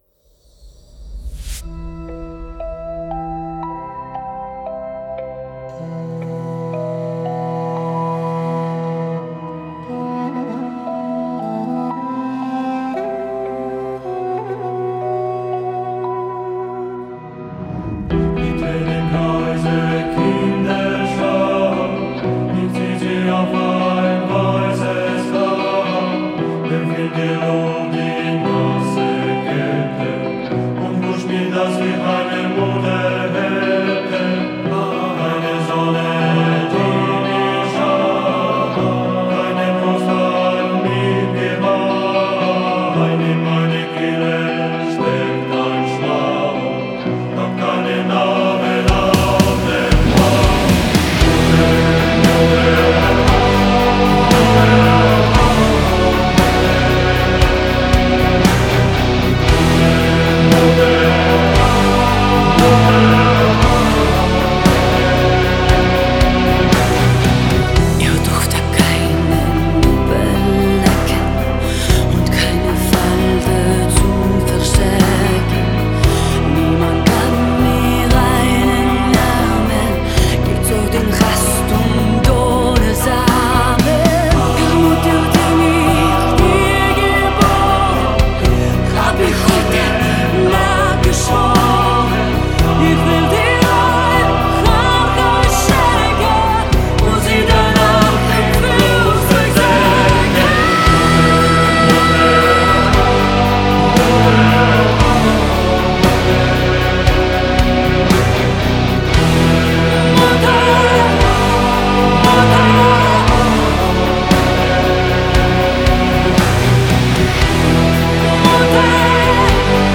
Уже доделал окончательно , перезаписали хор